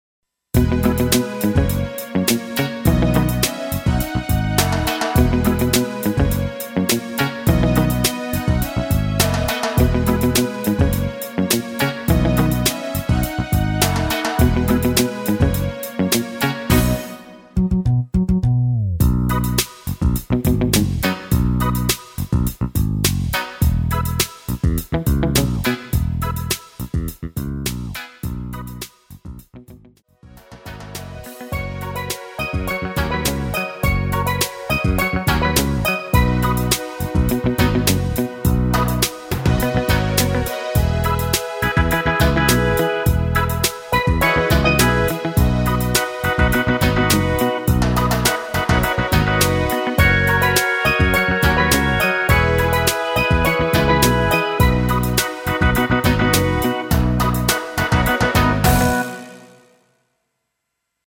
엔딩이 페이드 아웃이라 노래 부르기 편하게 아래의 가사 까지 하고 엔딩을 만들었습니다.(미리듣기 참조)
앞부분30초, 뒷부분30초씩 편집해서 올려 드리고 있습니다.
중간에 음이 끈어지고 다시 나오는 이유는
곡명 옆 (-1)은 반음 내림, (+1)은 반음 올림 입니다.